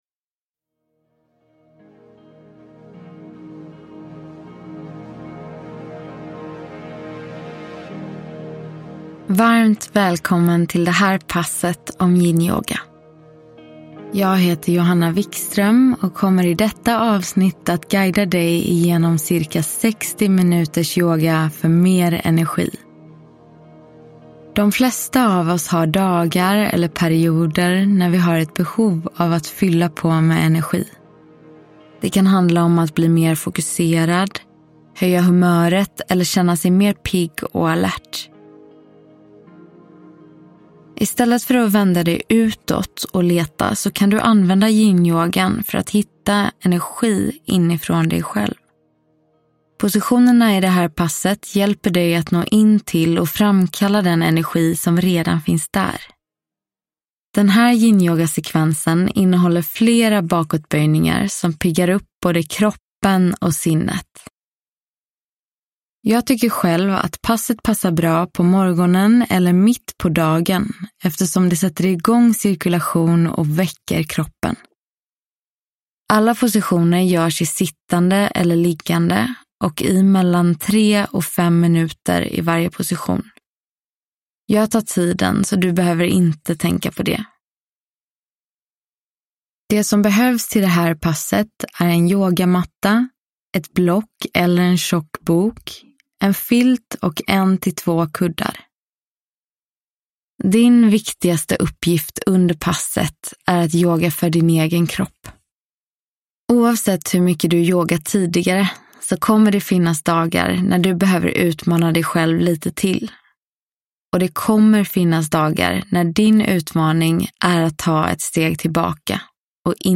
Yinyoga - Pass för energi – Ljudbok